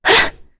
Tanya-Vox_Attack3.wav